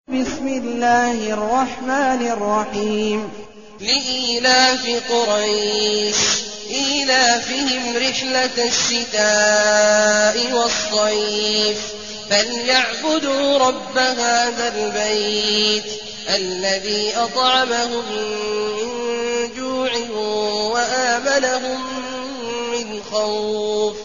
المكان: المسجد النبوي الشيخ: فضيلة الشيخ عبدالله الجهني فضيلة الشيخ عبدالله الجهني قريش